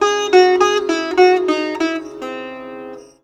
SITAR LINE28.wav